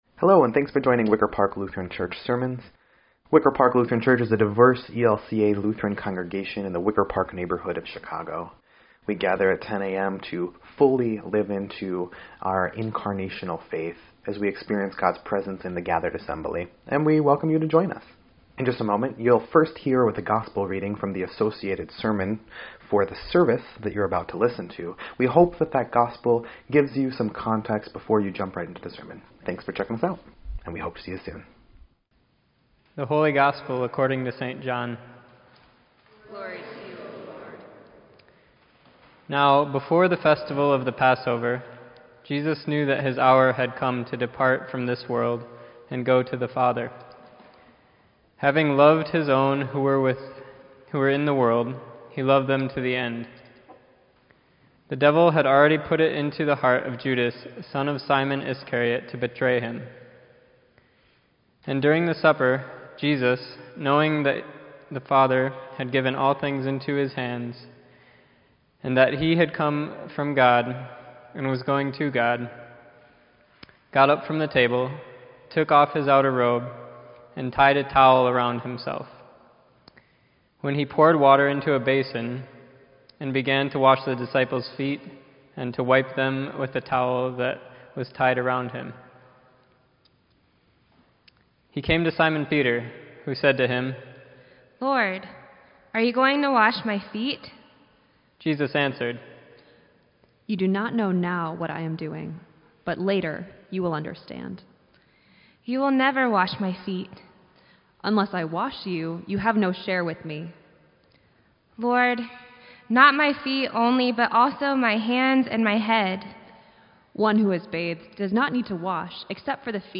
Sermon_3_29_18_EDIT.mp3